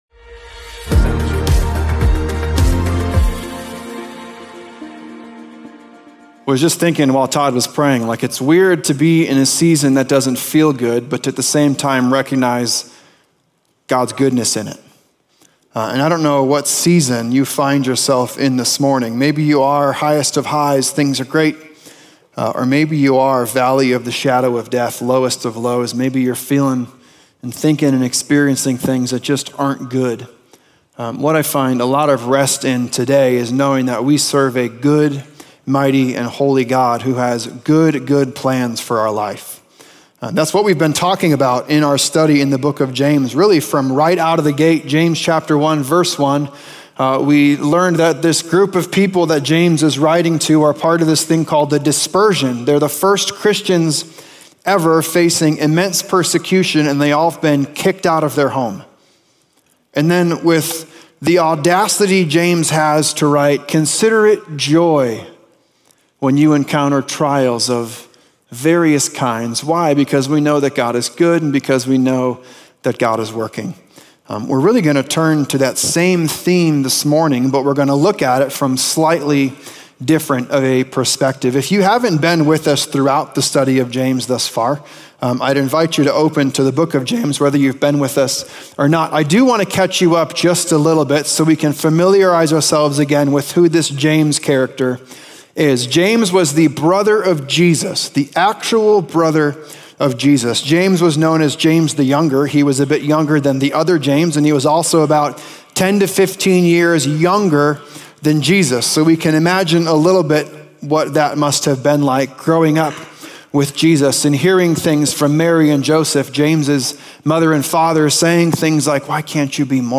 Highlands Church